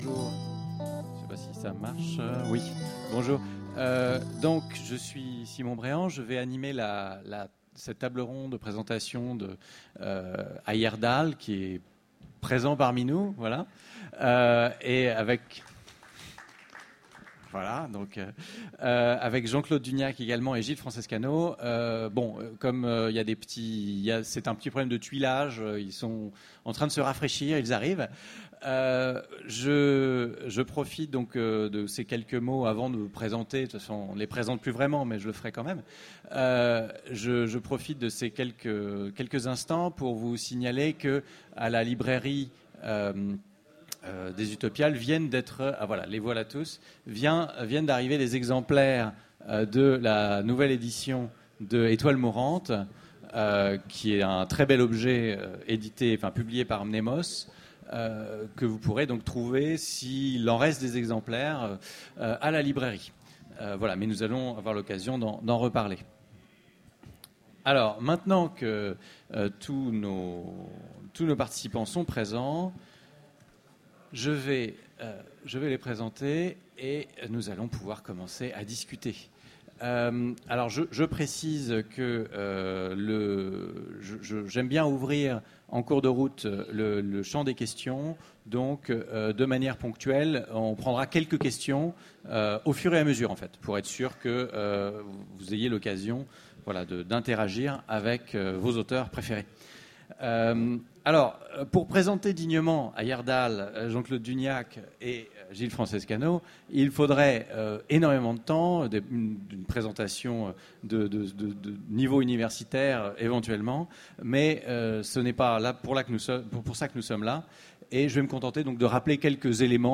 Utopiales 2014
Mots-clés Rencontre avec un auteur Conférence Partager cet article